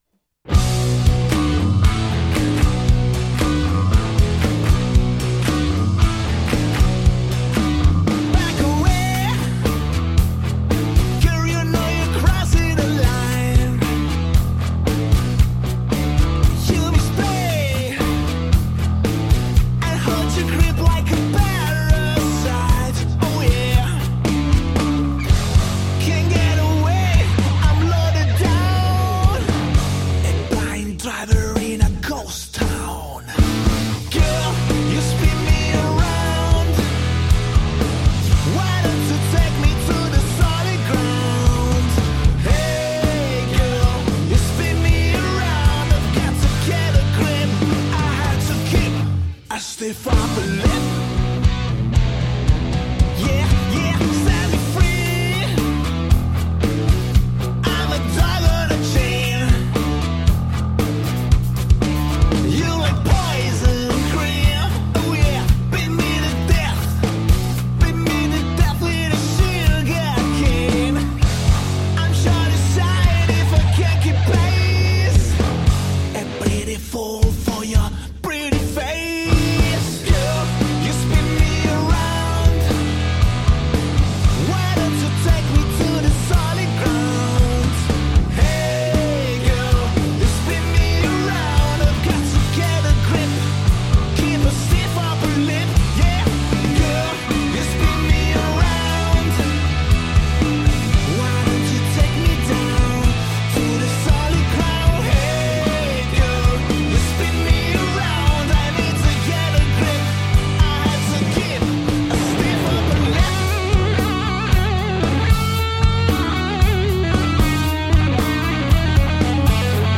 intervista-ai-dobermann-6-12-21.mp3